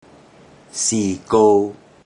Click each Romanised Teochew word to listen to how the Teochew word is pronounced.
si54kou3.mp3